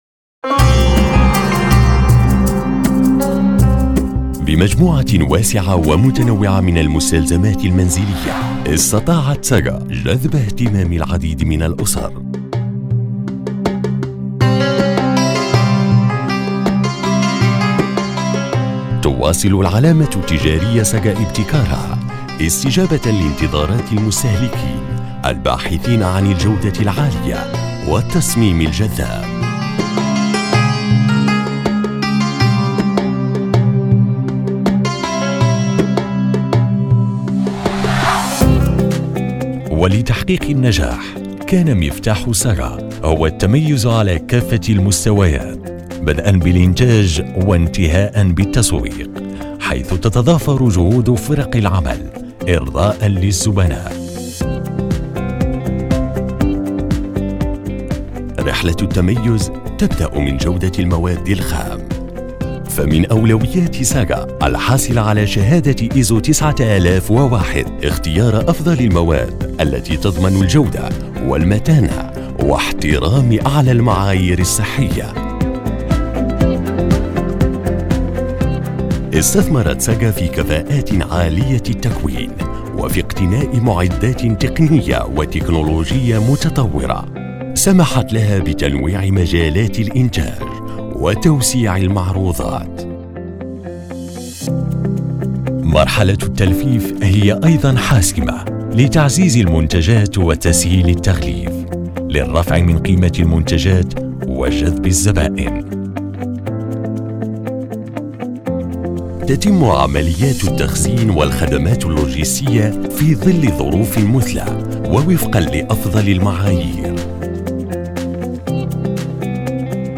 Male
Adult (30-50)
An experienced voice over, elegant and warm voice, with a wide range of playing styles and tones. A timbre from the medium to serious adapting to all future projects and supports.
All our voice actors have professional broadcast quality recording studios.
Male Voice Over Talent